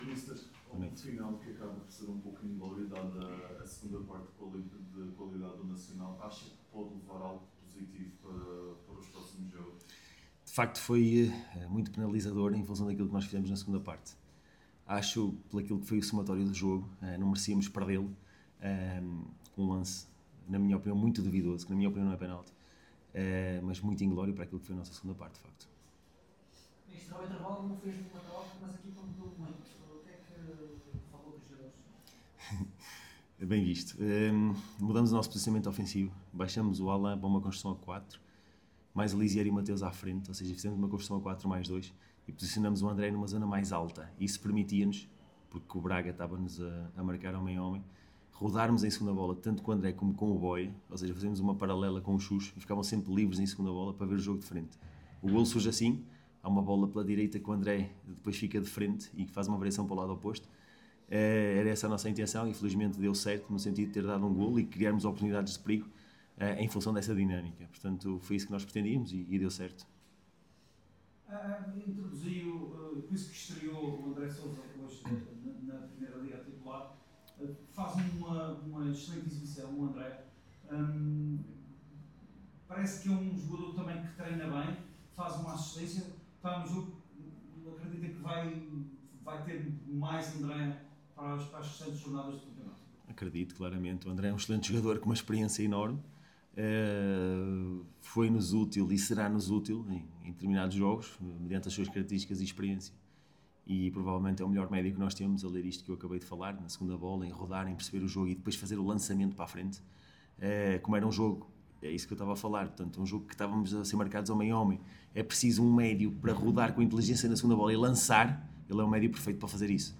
Na conferência de imprensa realizada no final do encontro para a 24.ª jornada da Liga Portugal Betclic